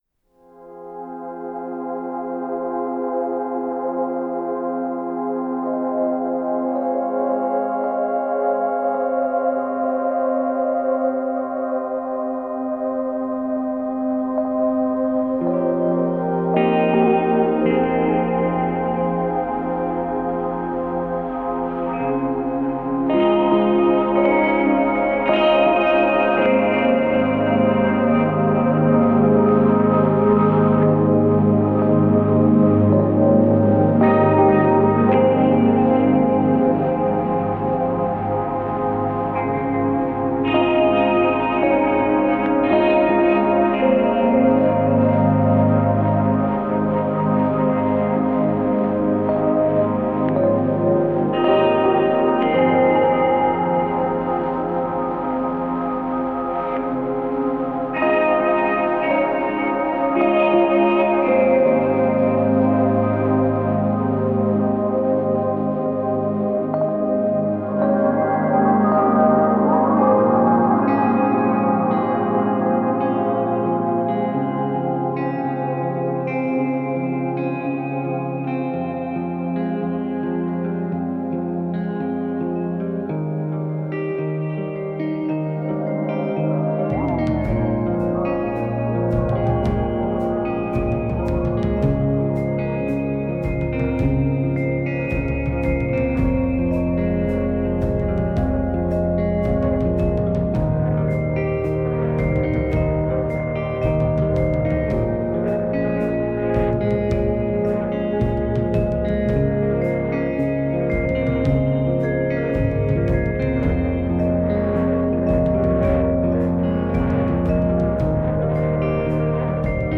Calm song I found
It is nice and calm.